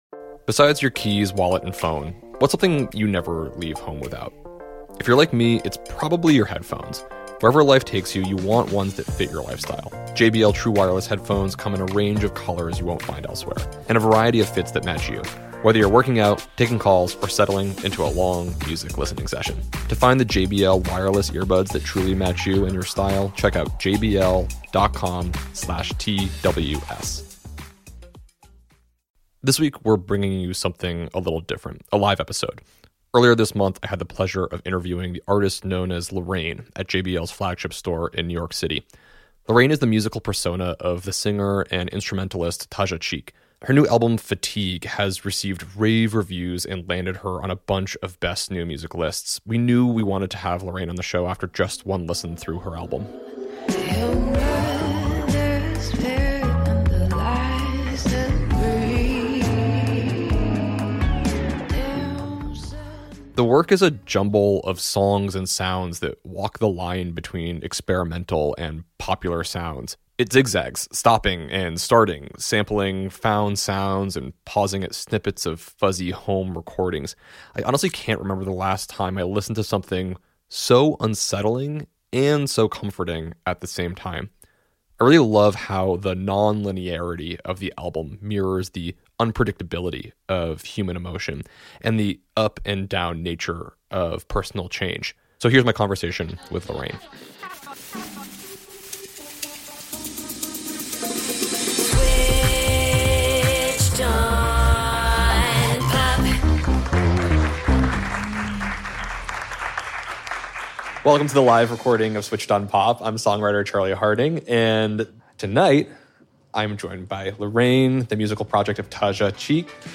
(live at JBL)